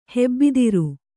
♪ hebbidiru